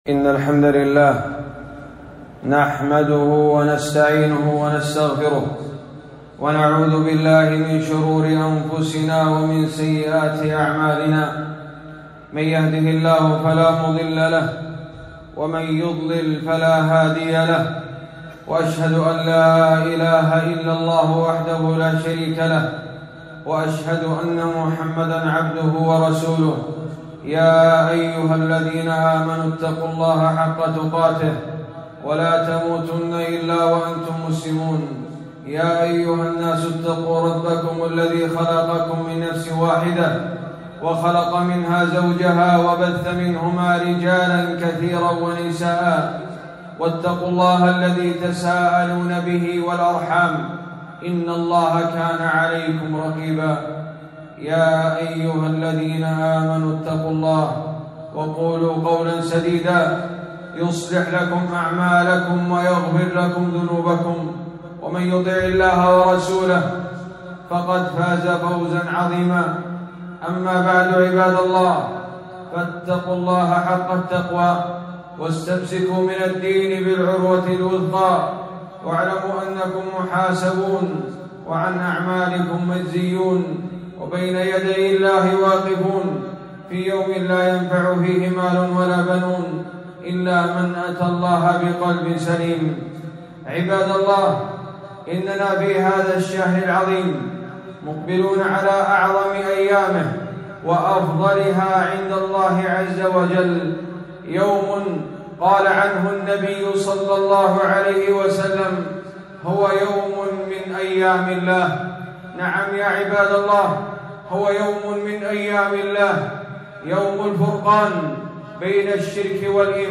خطبة - يوم عاشوراء فضائل وأحكام ورد شبهات